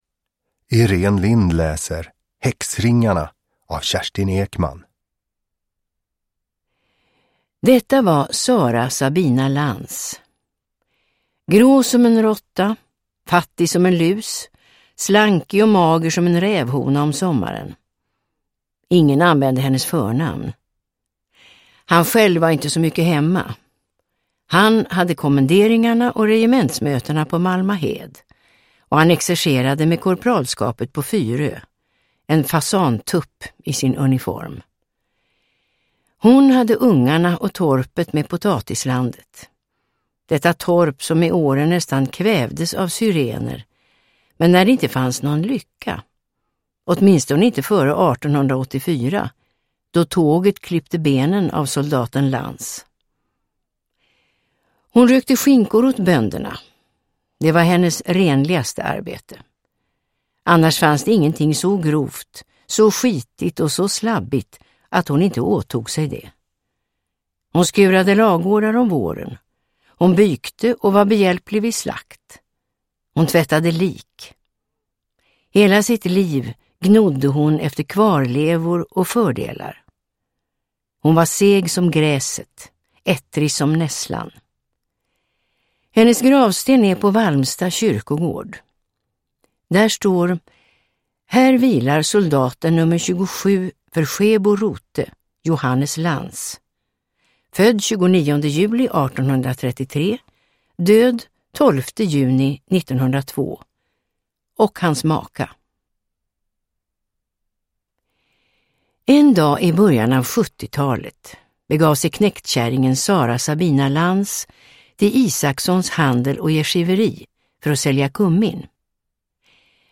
Uppläsare: Irene Lindh
Ljudbok